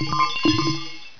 Notes: VERY annoying sound.